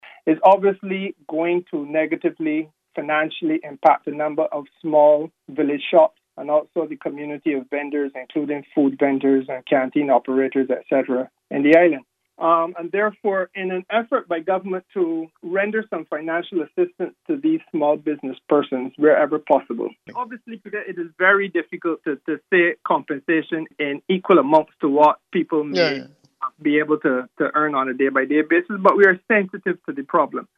The disclosure was made this afternoon by Minister Small Business and Entrepreneurship , Kerrie Symmonds, on VOB’s Getting Down to Brass Tacks call-in program.